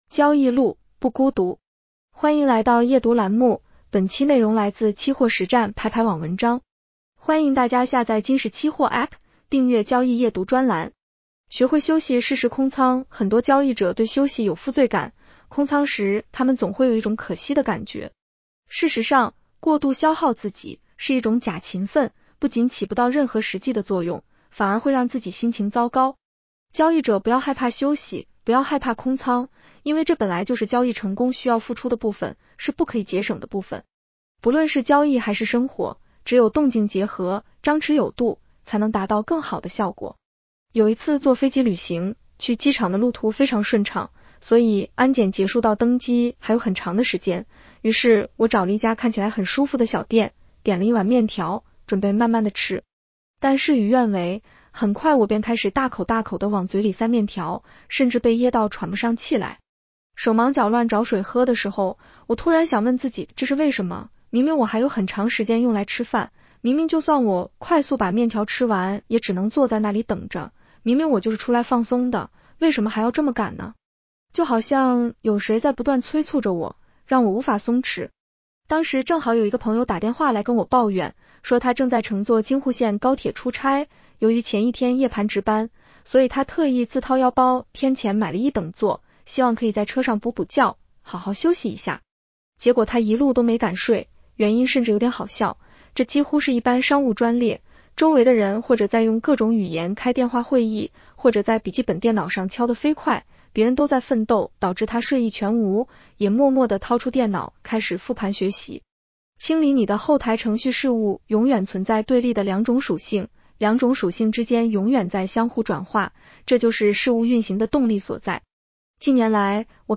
女声普通话版 下载mp3 学会“休息” 适时空仓 很多交易者对休息有“负罪感”，空仓时他们总会有一种“可惜”的感觉。